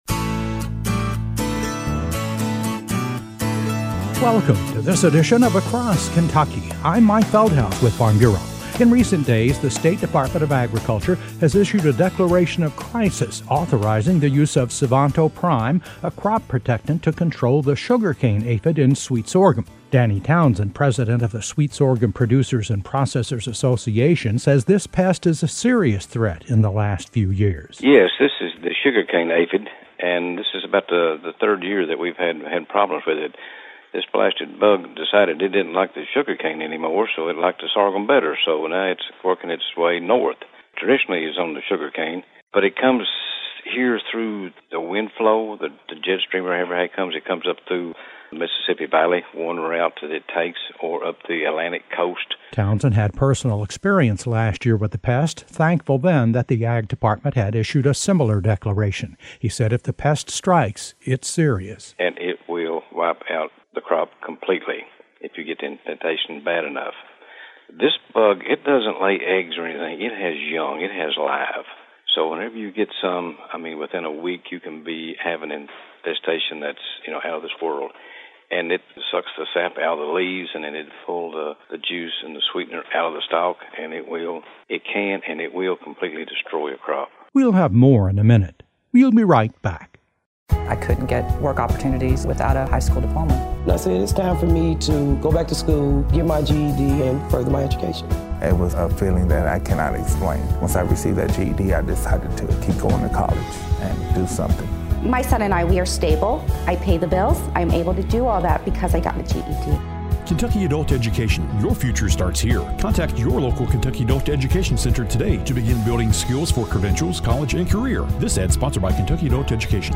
A report on a new pest that’s threatening Kentucky’s growing sweet sorghum crop.